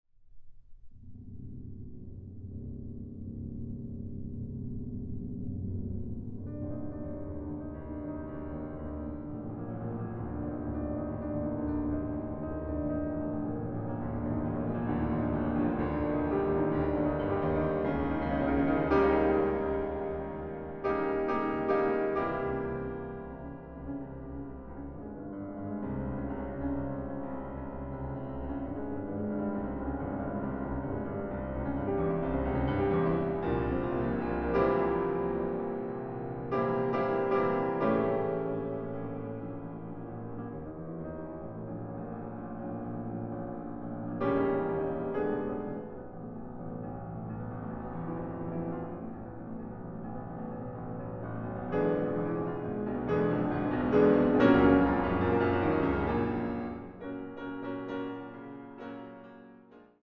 Klavier
Aufnahme: Mendelssohn-Saal, Gewandhaus Leipzig, 2024
ausgeführt im monumentalen Klang zweier Konzertflügel.
eine klangvolle und farbige Fassung für zwei Klaviere